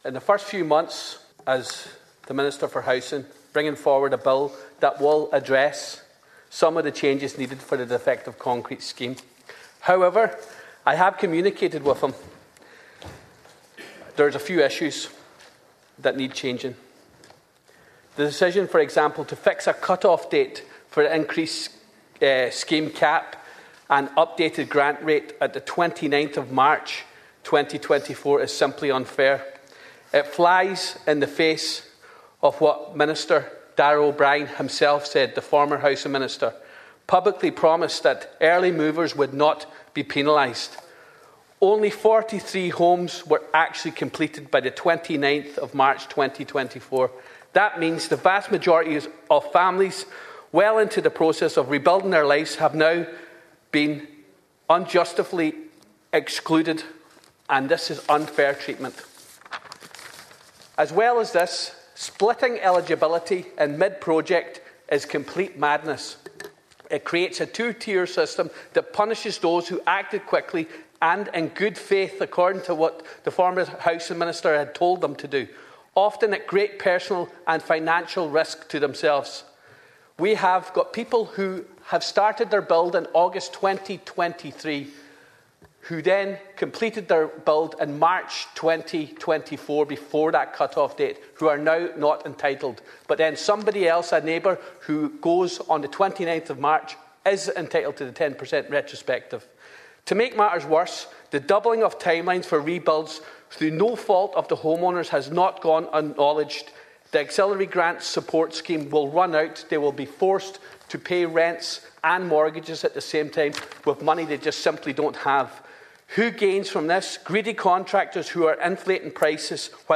The 100% Redress TD says nobody should be left behind: